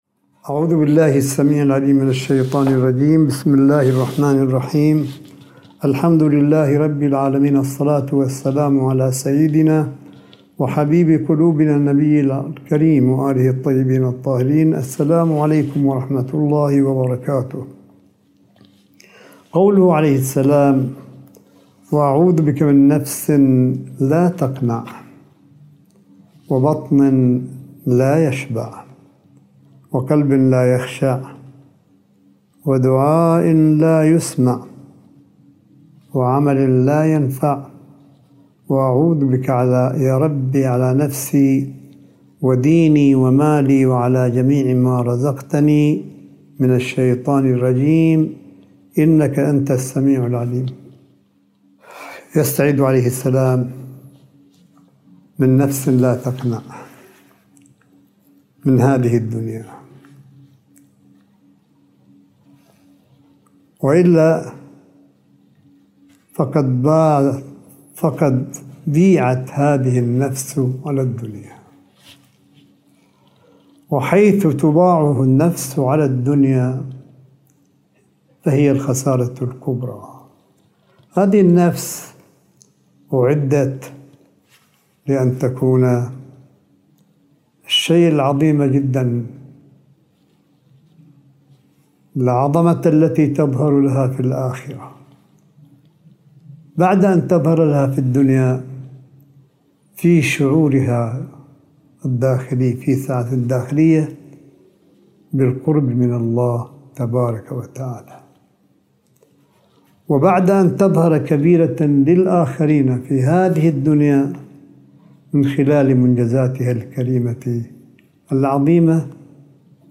ملف صوتي للحديث الرمضاني (28) لسماحة آية الله الشيخ عيسى أحمد قاسم حفظه الله – 29 شهر رمضان 1442 هـ / 11 مايو 2021م